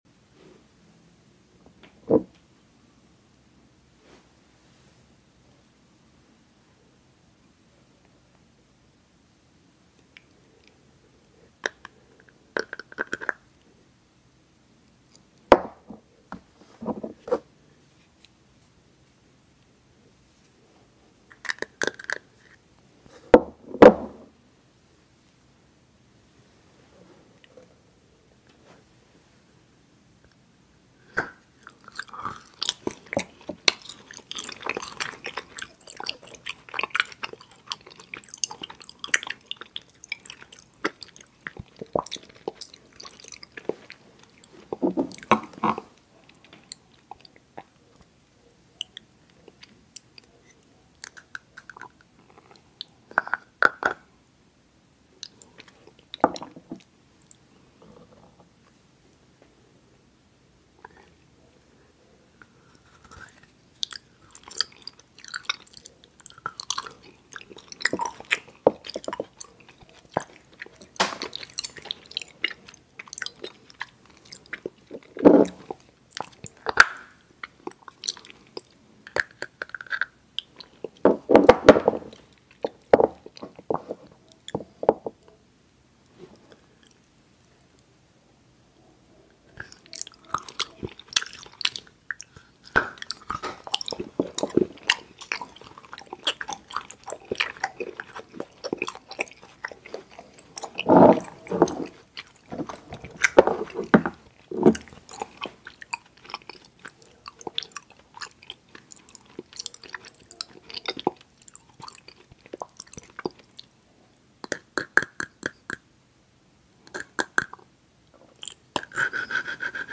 MOUTH EATING SOUNDS NO TALKING *CHOCOLATE FOOD TOOLS*